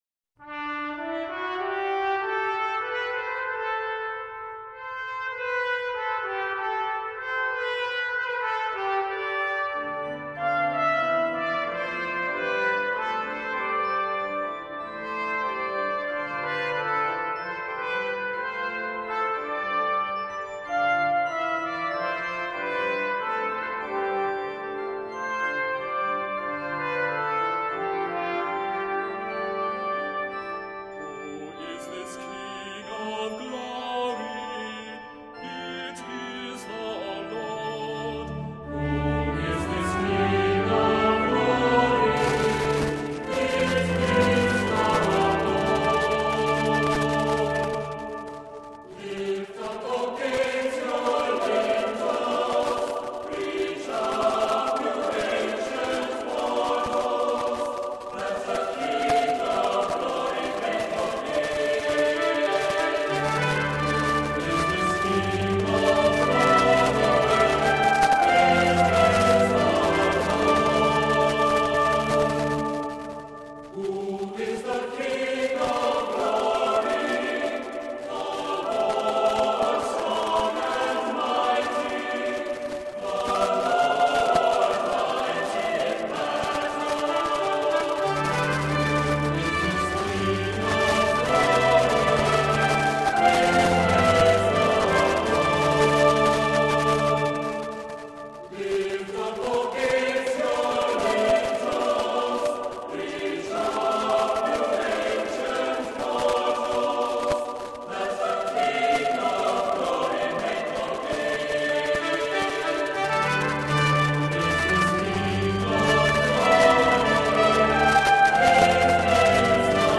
Voicing: Assembly, cantor,Soprano Descant,SATB